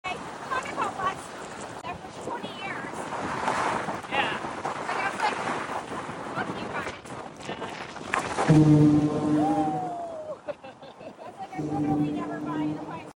You can hear this horn sound effects free download
You can hear this horn all across SF on a foggy day.
Was def louder in real life. Biking right over it.